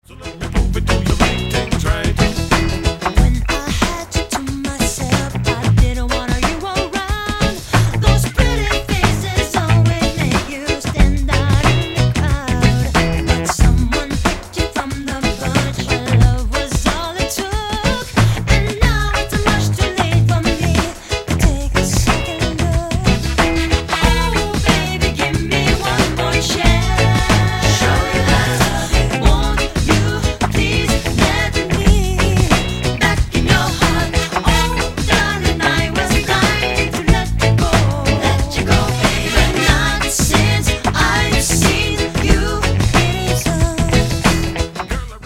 • Genre: Contemporary island, pop.